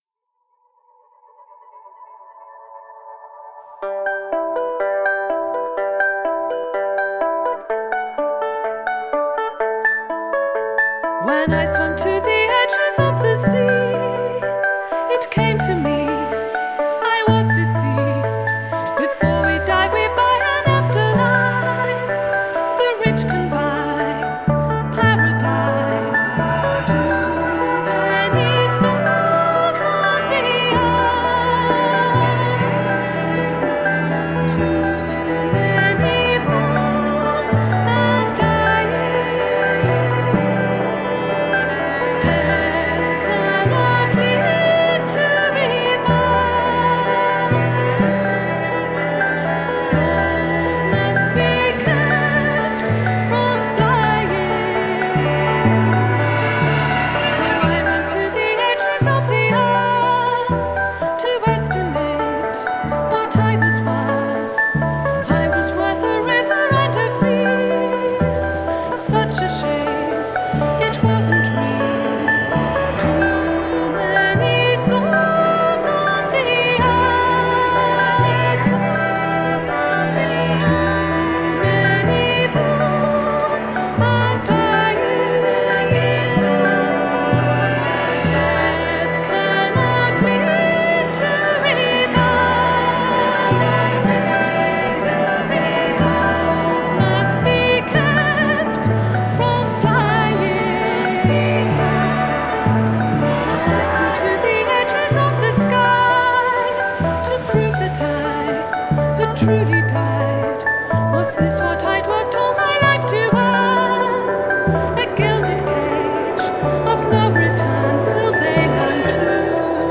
Mélangeant folk et parfois country